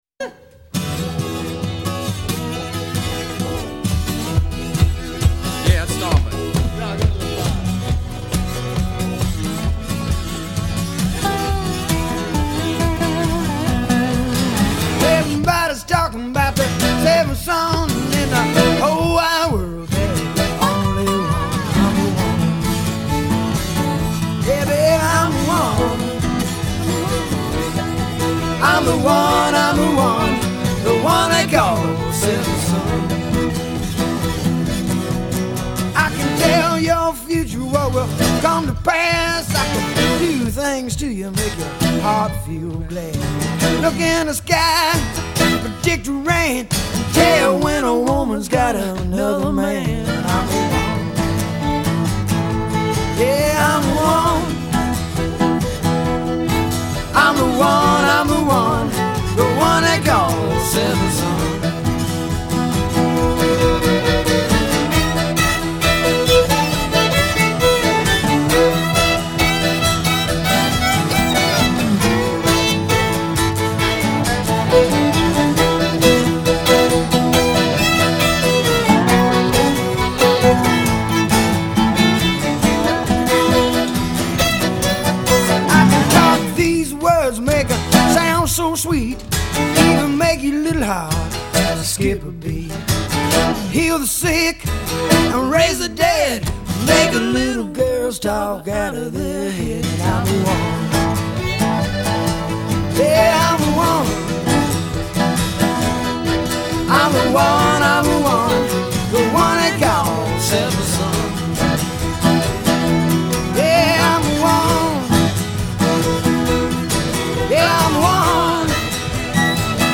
straight off the studio floor, no overdubs.